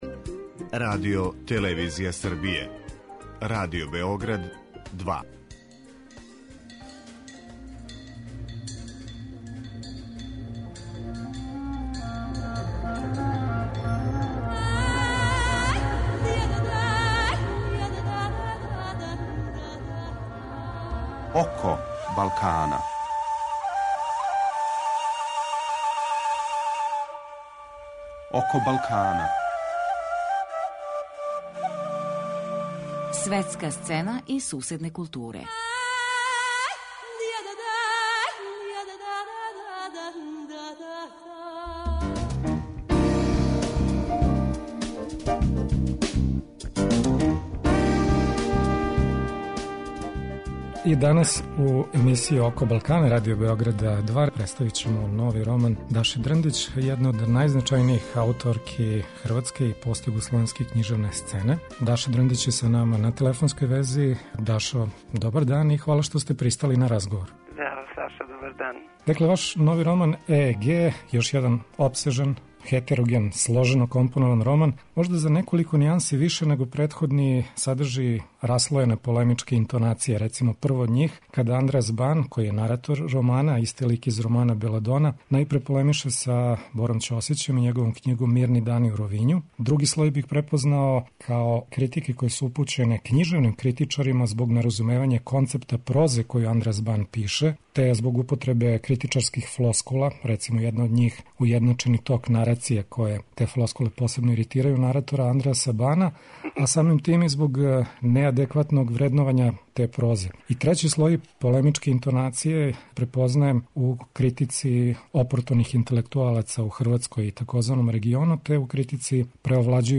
У првом делу емисије чућемо разговор са ријечком прозаисткињом Дашом Дрндић поводом њеног романа "ЕЕГ".